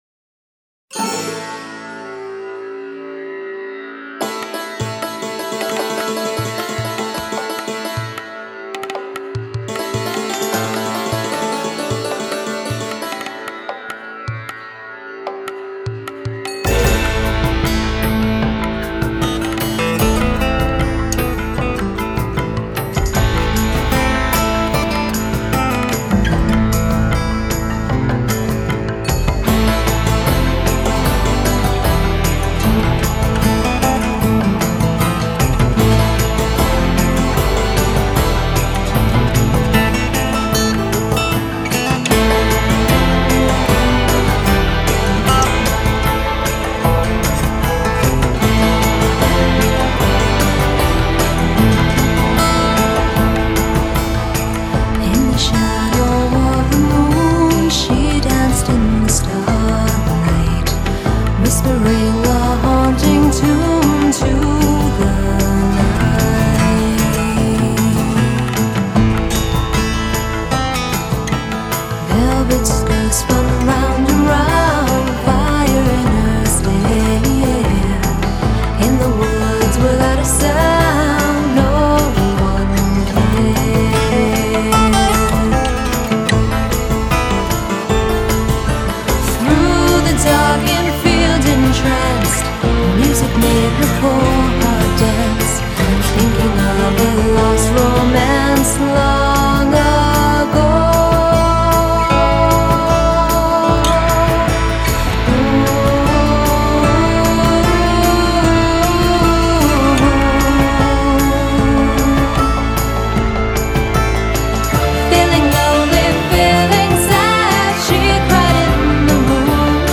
来自欧洲中世纪风格民谣乐队
潜心畅游木吉他、曼陀铃、锡哨、提琴、铃鼓、手摇风琴等奏响的和声之中 ……